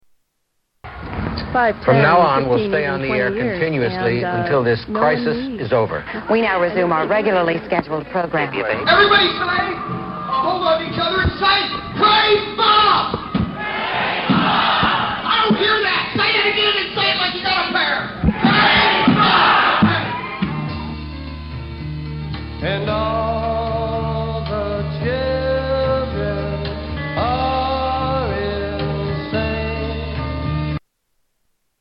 Tags: Radio Radio Stations Station I.D. Seques Show I.D